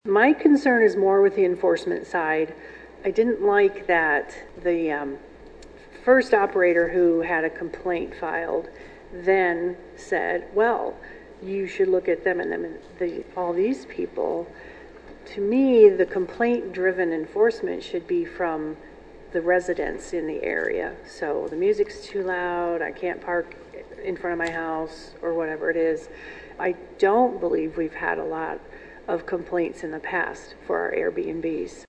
Council revisited the new rules during their meeting Tuesday night.
Councilwoman Ann Parks voiced concern that one vague complaint triggered the city-wide short-term rental crackdown.